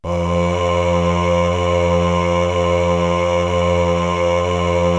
Index of /90_sSampleCDs/Best Service ProSamples vol.55 - Retro Sampler [AKAI] 1CD/Partition C/CHOIR